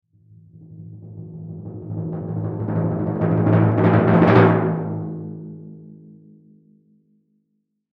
Звуки литавры
Литавра – есть такой вариант